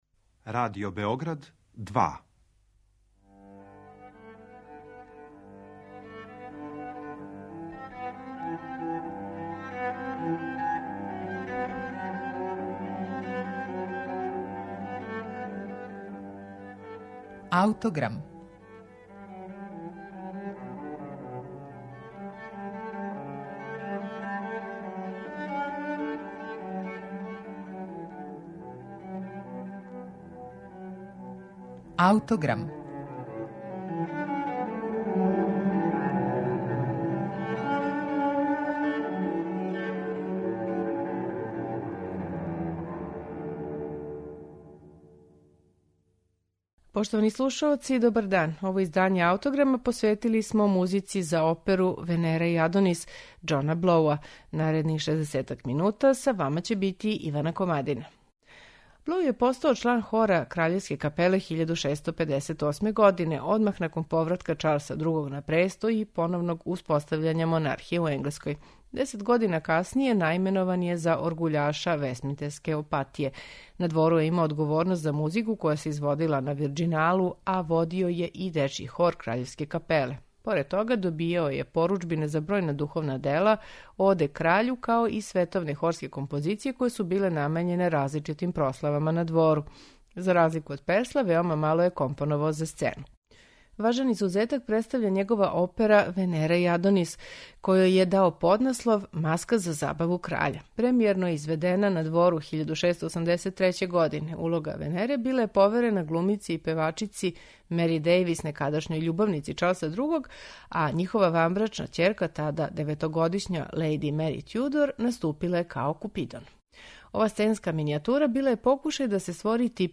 сопран
баритон
контратенор